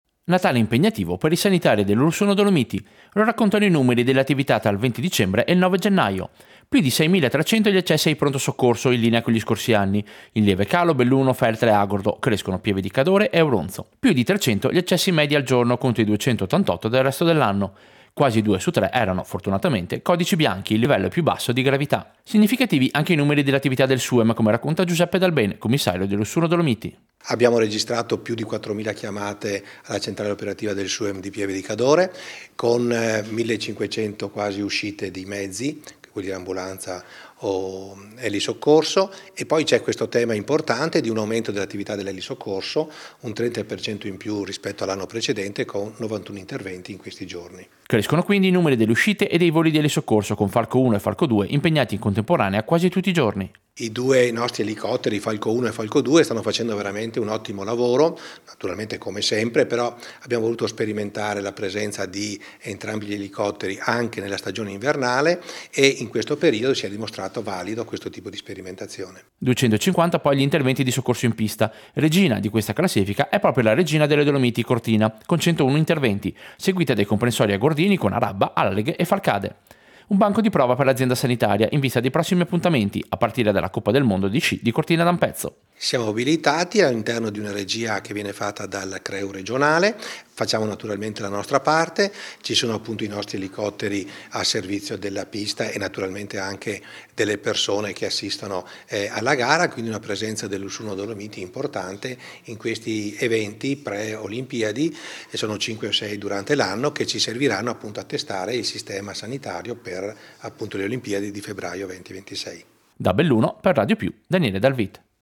Servizio-Dati-soccorsi-feste.mp3